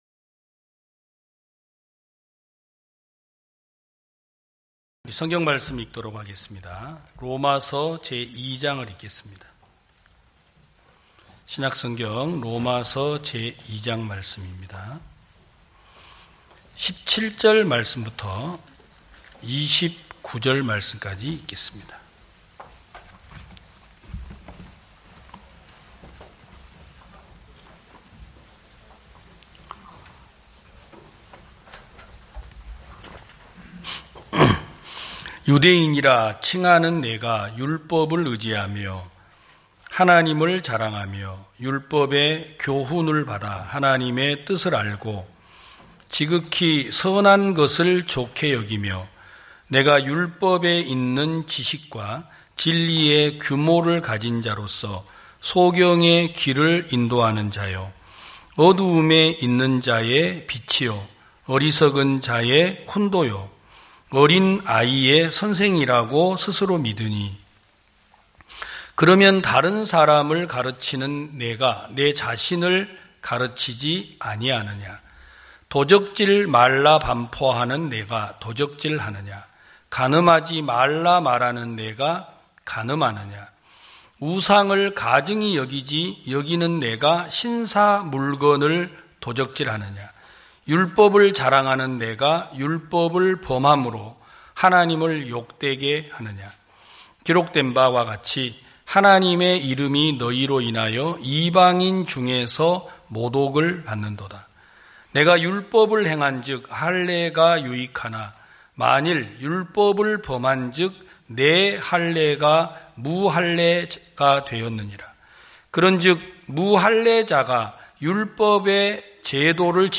2022년 05월 01일 기쁜소식부산대연교회 주일오전예배
성도들이 모두 교회에 모여 말씀을 듣는 주일 예배의 설교는, 한 주간 우리 마음을 채웠던 생각을 내려두고 하나님의 말씀으로 가득 채우는 시간입니다.